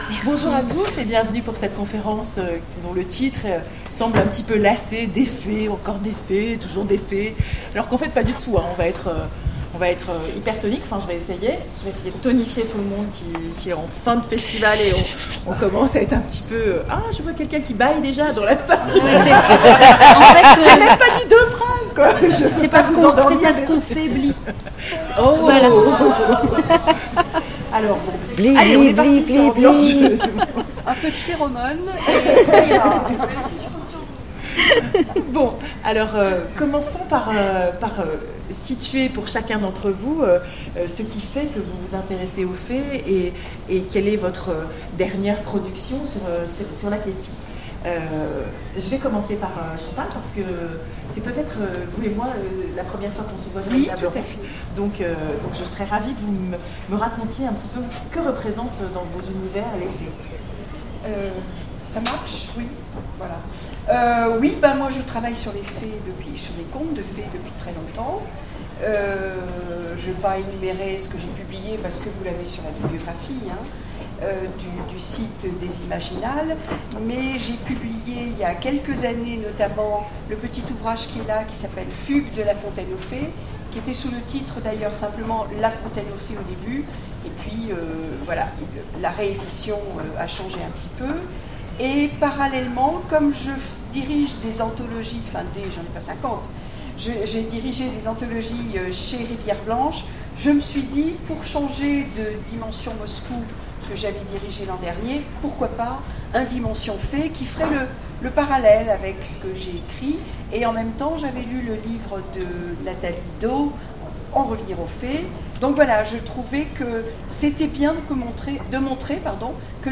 Imaginales 2016 : Conférence Des fées, encore des fées…
Imaginales_2016_conference_toujours_des_fees_ok.mp3